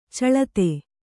♪ caḷate